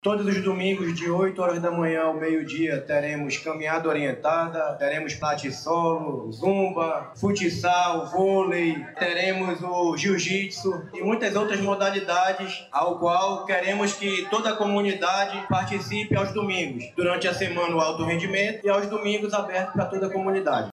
Dentre as atividades ofertadas estão futebol, futsal, corrida e zumba, conforme explicou o secretário de Estado de Esporte e Lazer, Diego Américo.